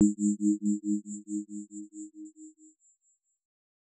tone3.L.wav